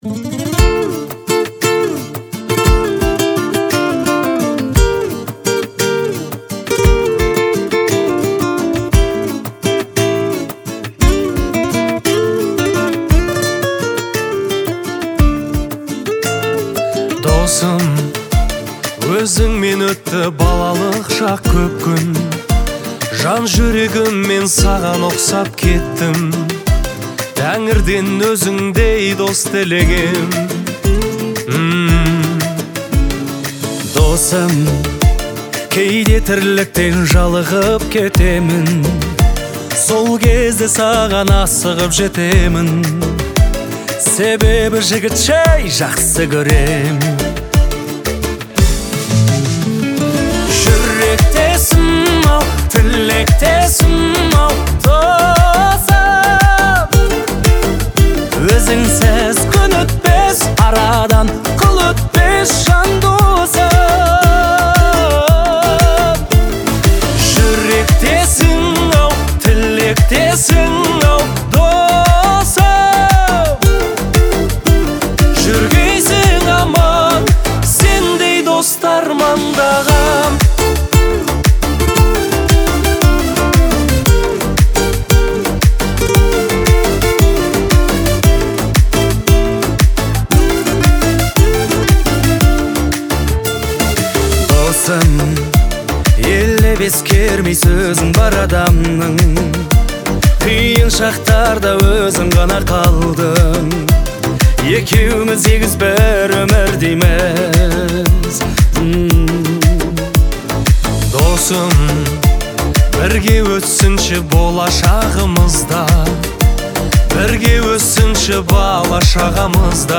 казахского поп-музыки